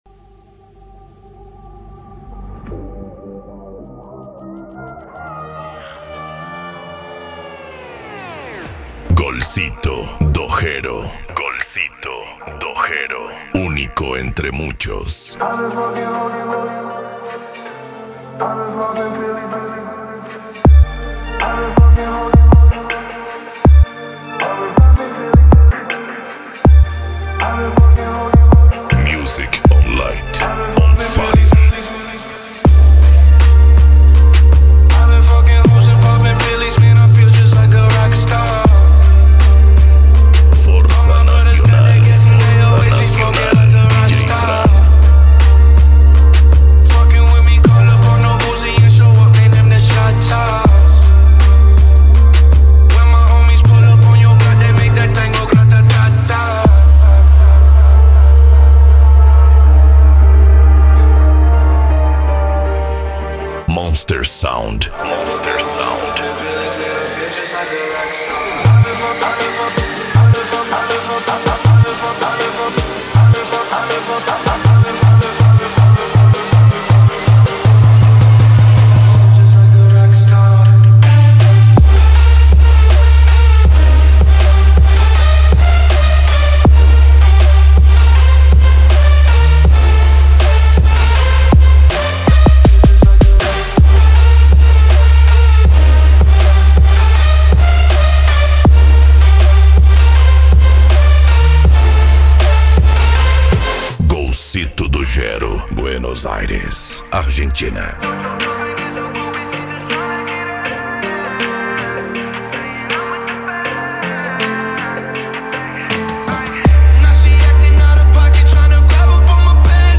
Bass
PANCADÃO
Remix